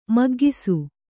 ♪ maggisu